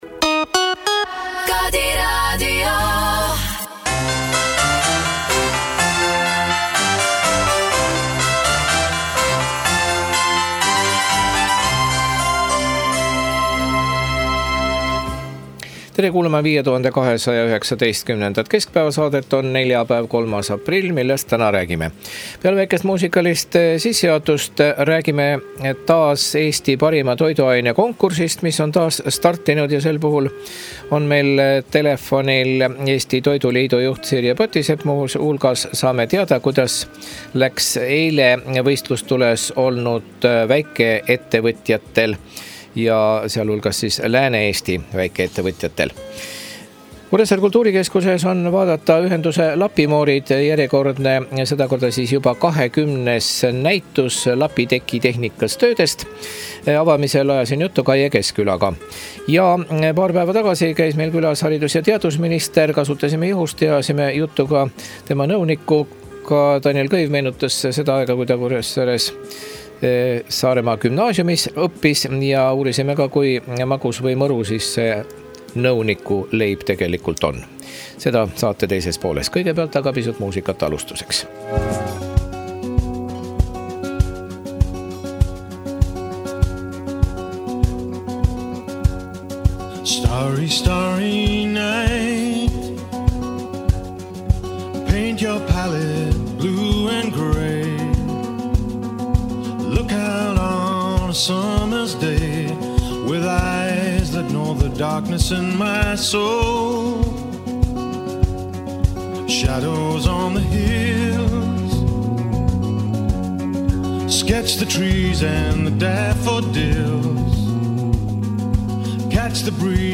Teisipäeval salvestasime intervjuu